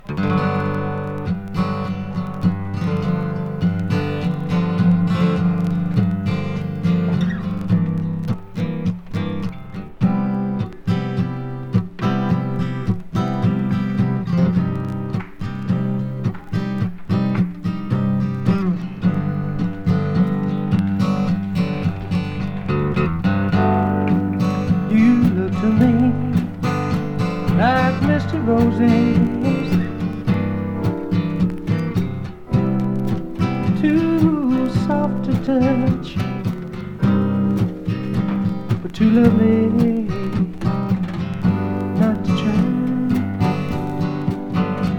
Rock, Pop　USA　12inchレコード　33rpm　Stereo